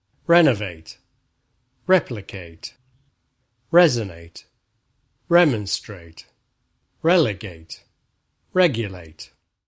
Again, non-natives often get these wrong, not realizing that in native speech they begin like wreck:
Half a dozen of them end in -ate: rénovate, réplicate, résonate, rémonstrate, rélegate, régulate.